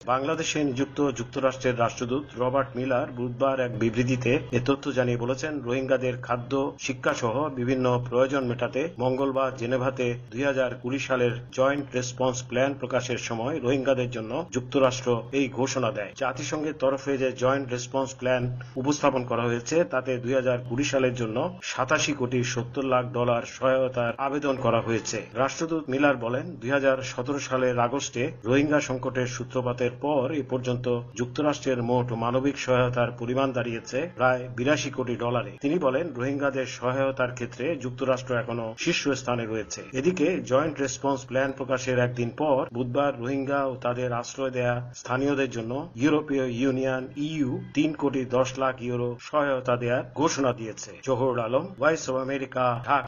কক্সবাজার থেকে